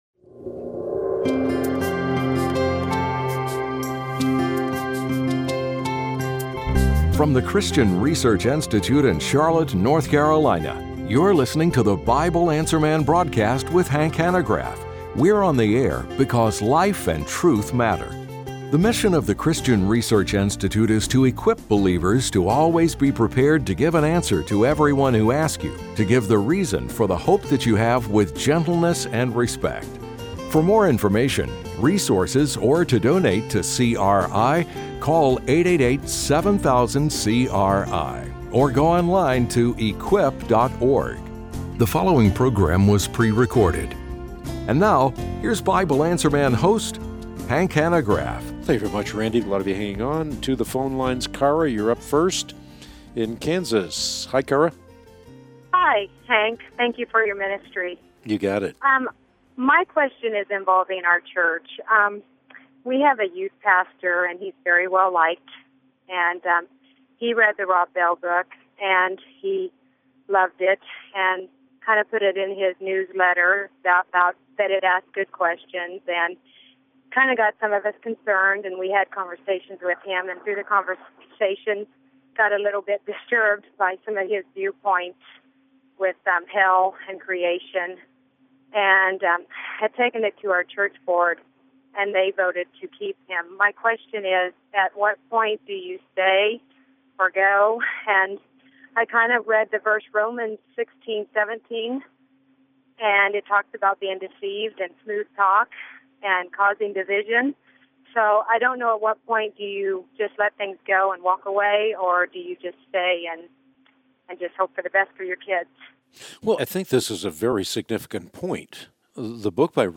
Q&A: Problematic Views on Hell, People Born Gay, and Mid-Tribulation Rapture | Christian Research Institute